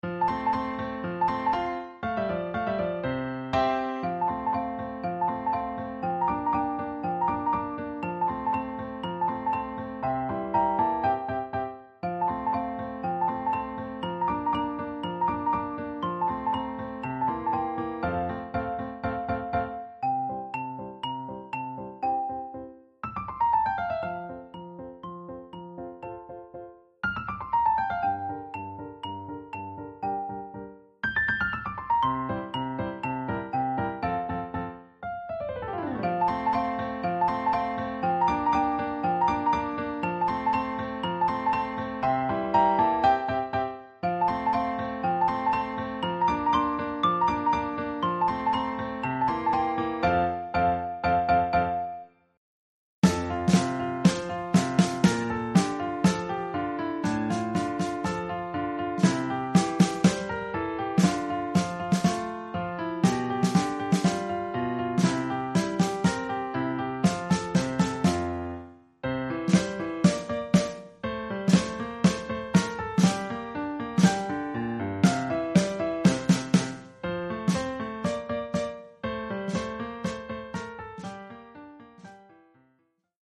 percussion (xylophone et caisse claire),
avec accompagnement de piano.
Instruments : xylophone et piano.
Instruments : caisse claire et piano.